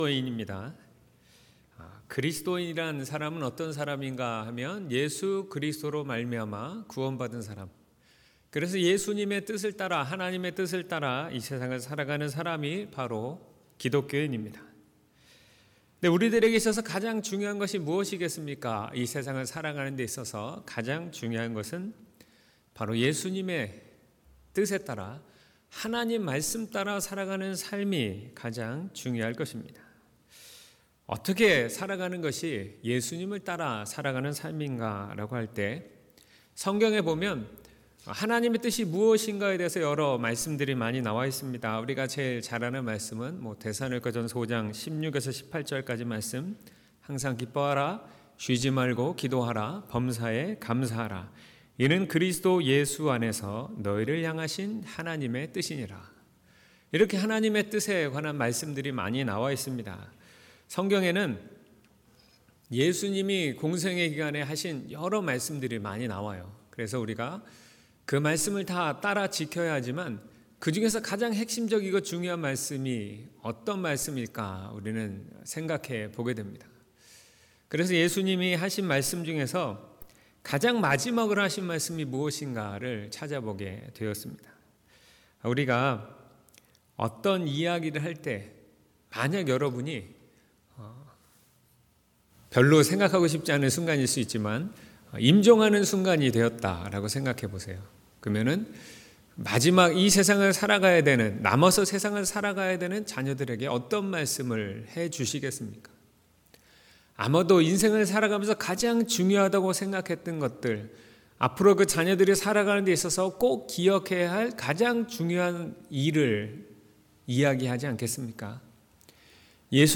2월10일 주일설교/ 주님의 마지막 명령 / 사도행전 1: 4-8